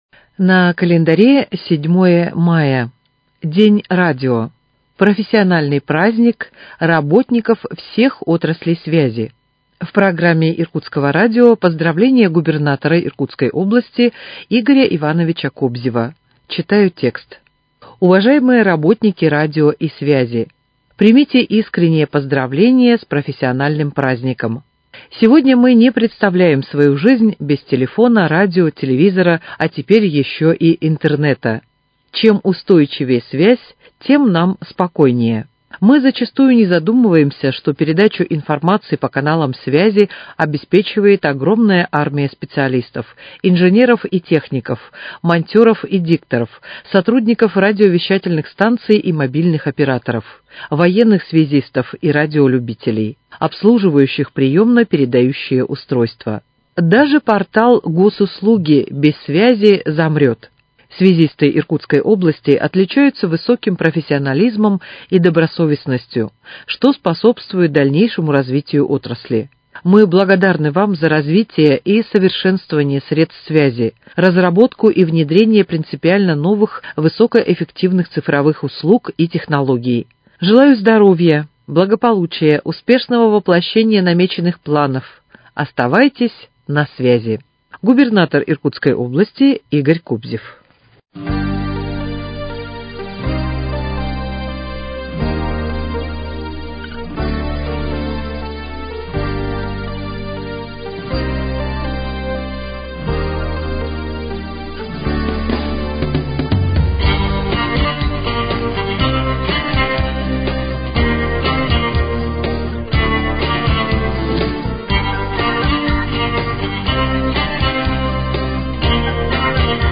Поздравление Губернатора Иркутской области Игоря Ивановича Кобзева.